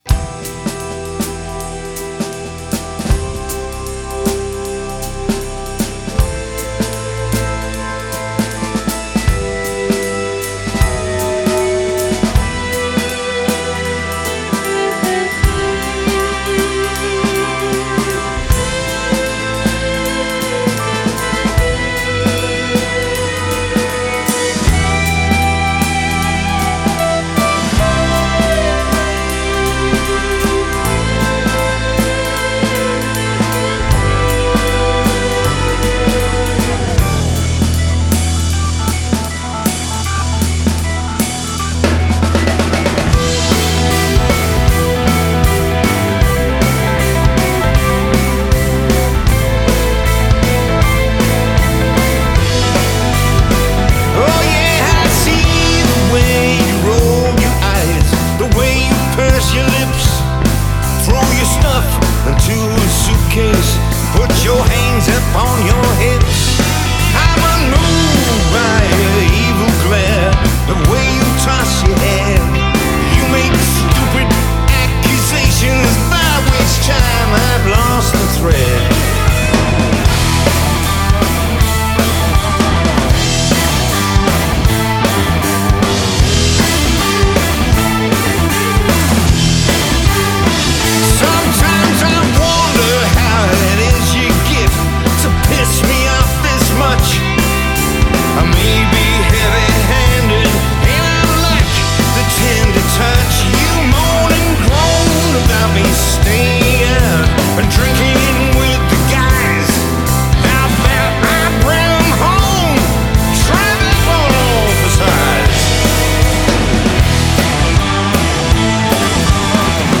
хард-рок